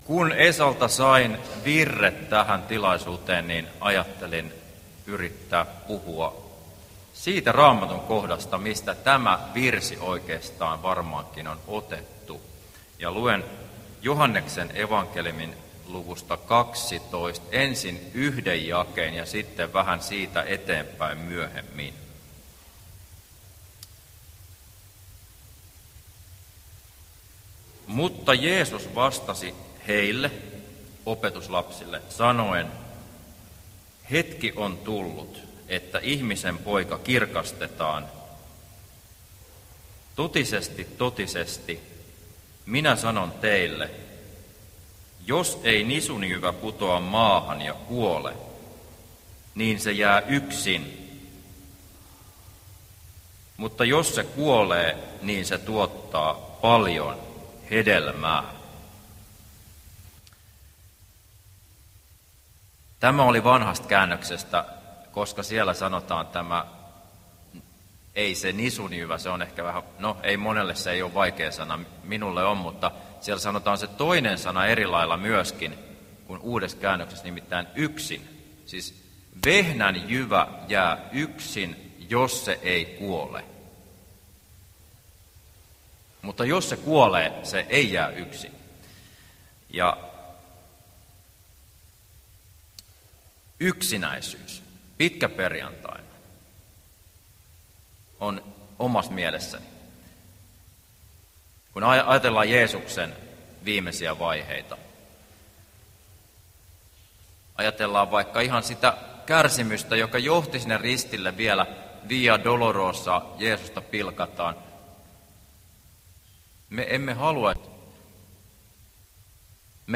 ristinjuhlassa Alavudella pitkäperjantaina